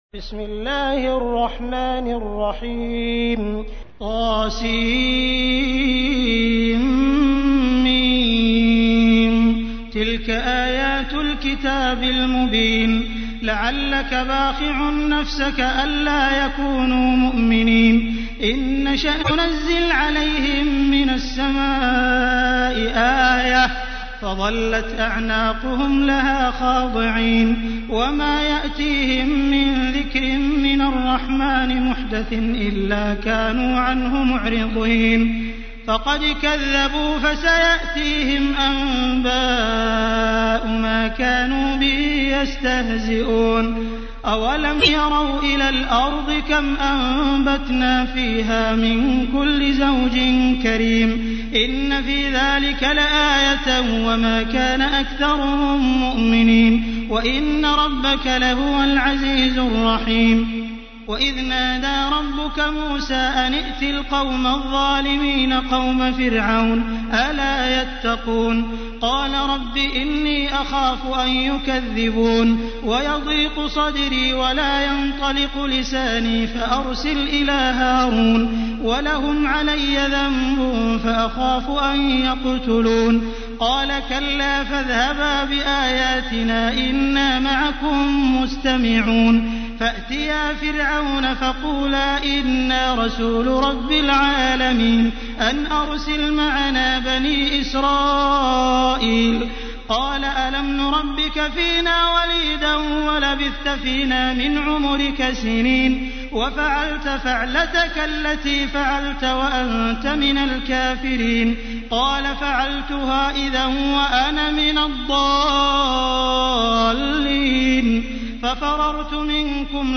تحميل : 26. سورة الشعراء / القارئ عبد الرحمن السديس / القرآن الكريم / موقع يا حسين